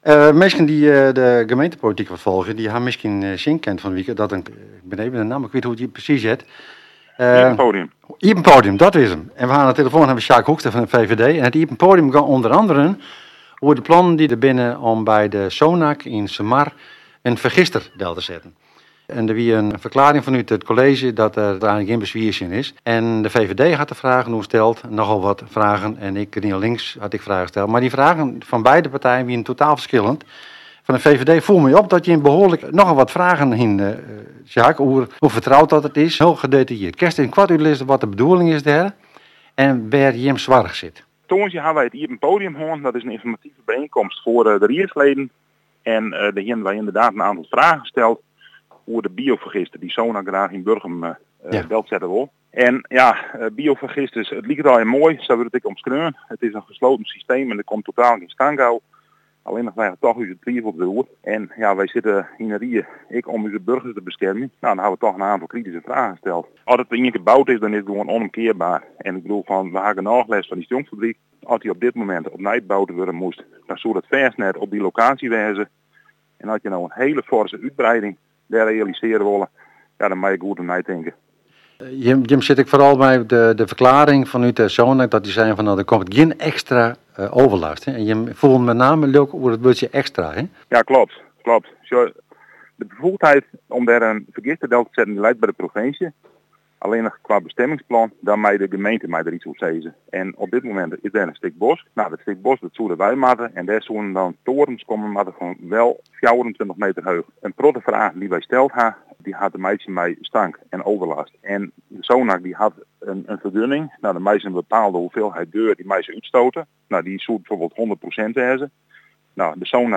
In het RTV NOF radioprogramma Op ‘e hichte op zaterdagochtend gaf raadslid Hoekstra een toelichting.
Luister hier naar het gesprek met Sjaak Hoekstra uit de uitzending van Op ‘e hichte van afgelopen zaterdag